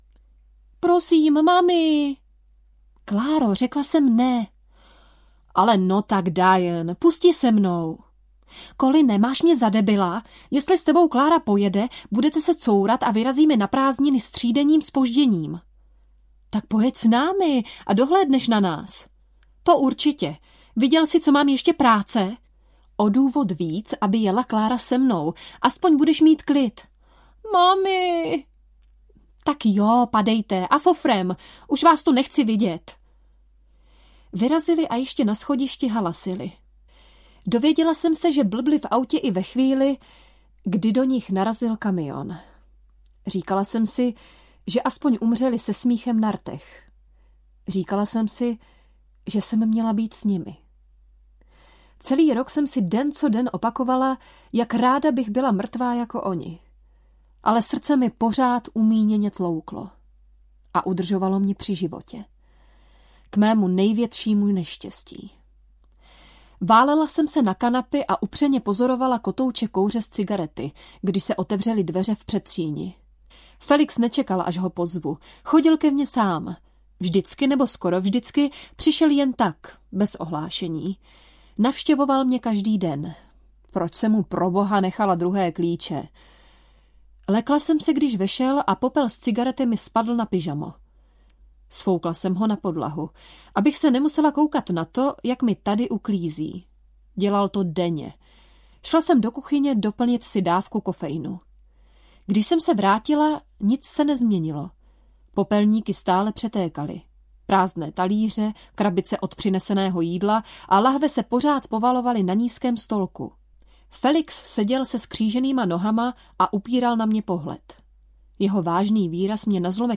Šťastní lidé čtou a pijou kávu audiokniha
Ukázka z knihy